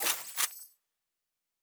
Sci-Fi Sounds
Weapon 13 Reload 1.wav